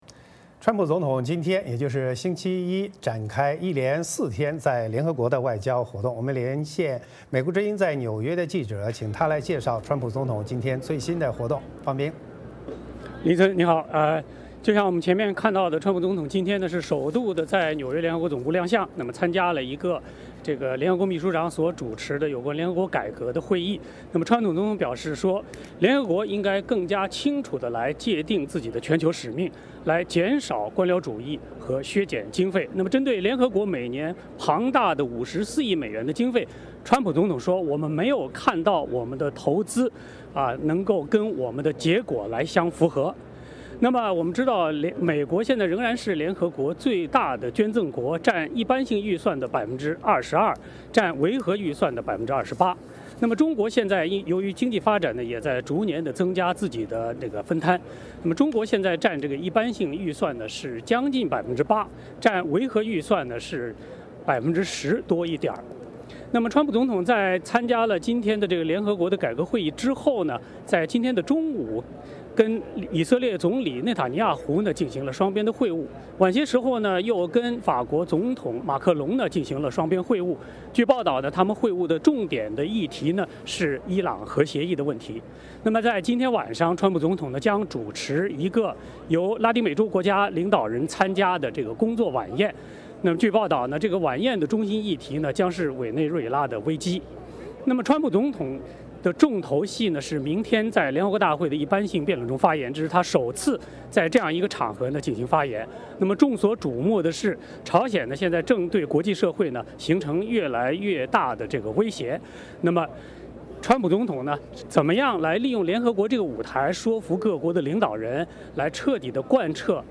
VOA连线：川普总统亮相联合国总部，展开外交活动